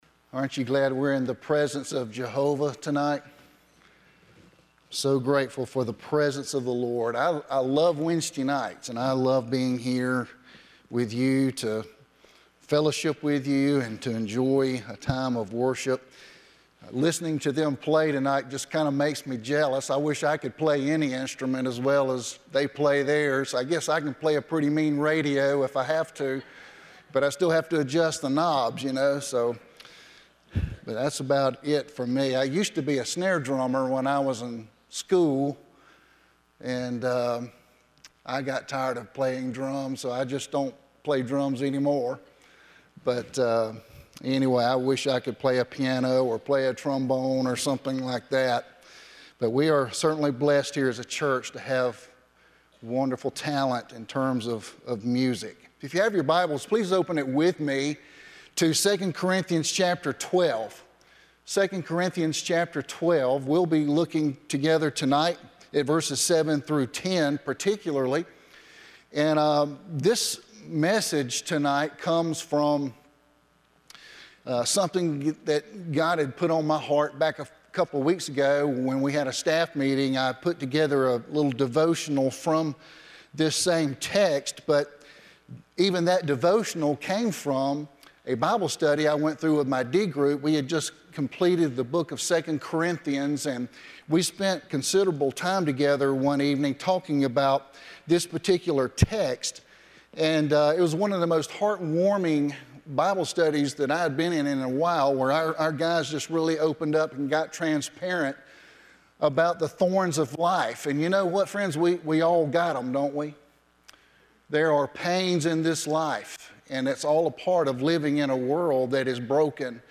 10-18-23pm Sermon Thorns of Life